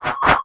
settings-pop-in.wav